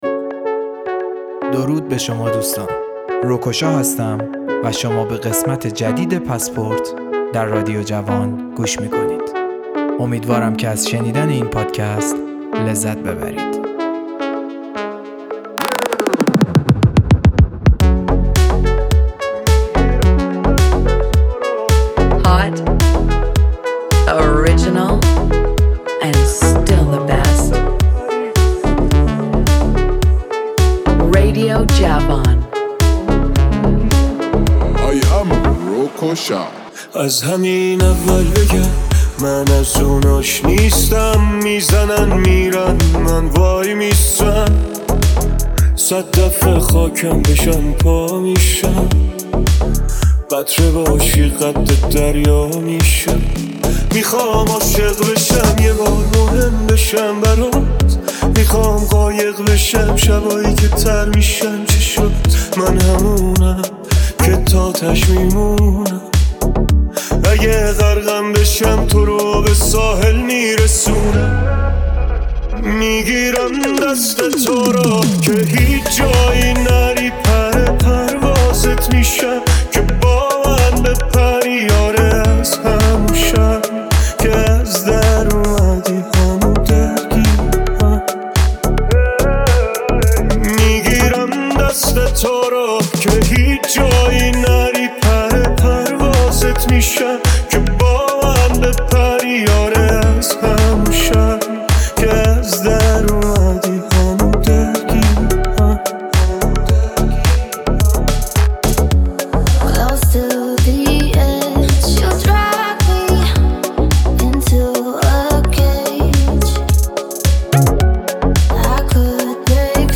بیس دار
الکترونیک